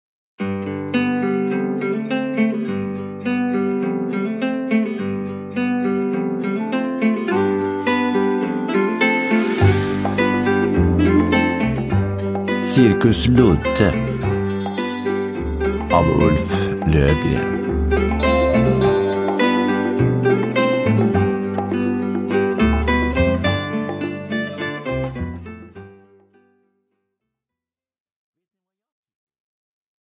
Cirkus Ludde – Ljudbok – Laddas ner